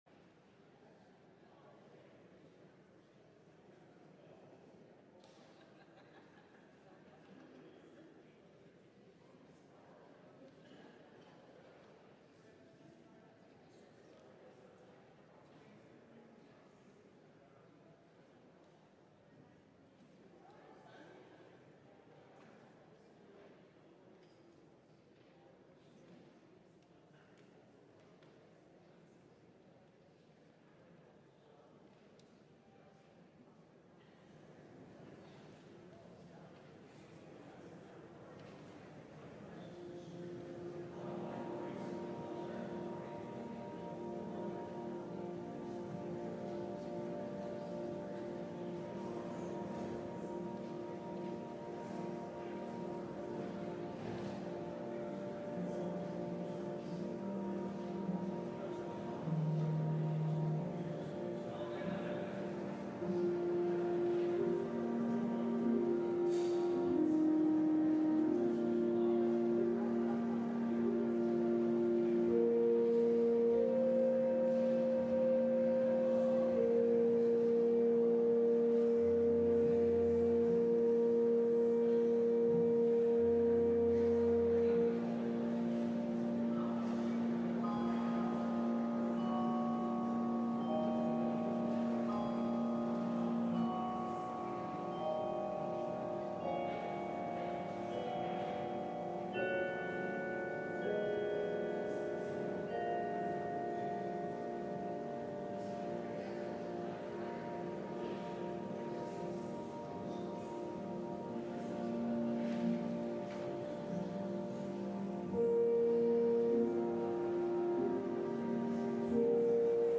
LIVE Morning Worship Service - Faith 101: Be Filled with the Spirit! (But How?)